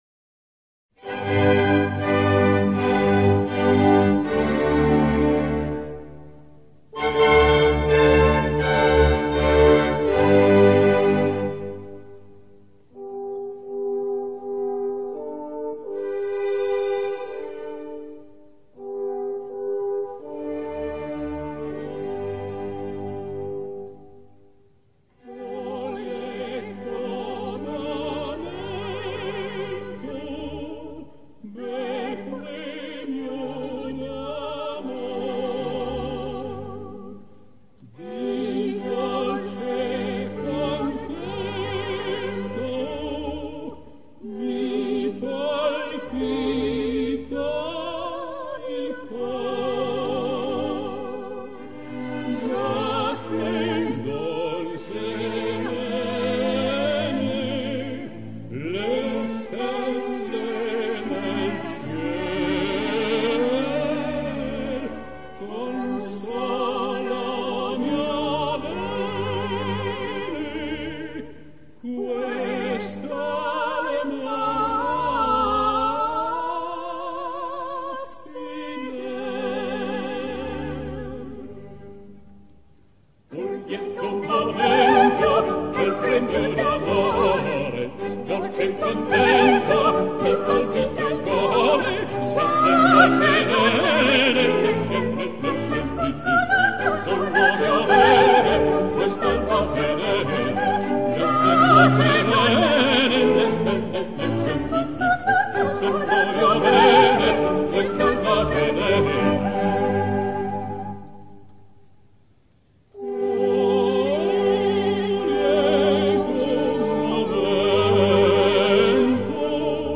au-clair-de-la-lune-boeldieu-voitures.mp3